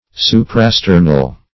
Search Result for " suprasternal" : The Collaborative International Dictionary of English v.0.48: Suprasternal \Su`pra*ster"nal\, a. (Anat.) Situated above, or anterior to, the sternum.